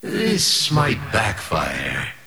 Worms speechbanks
Fire.wav